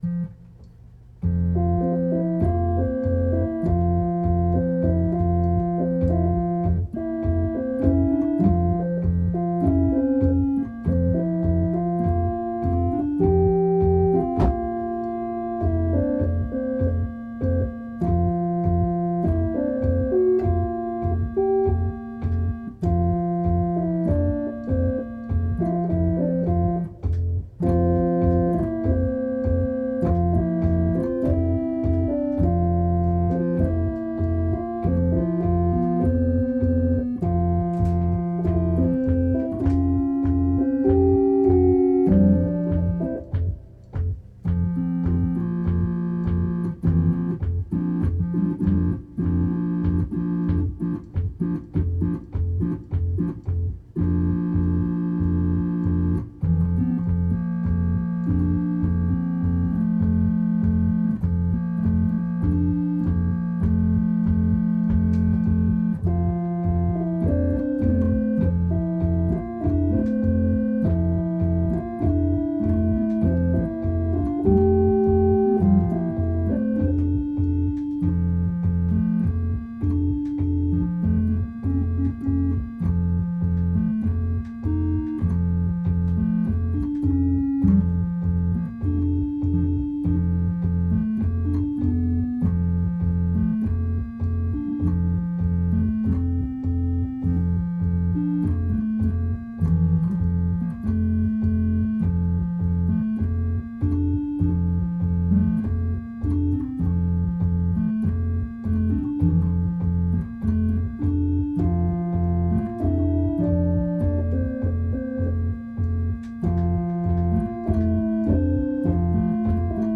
Noisy and sloppy but you might be able to hear some of the goodness hidden in there.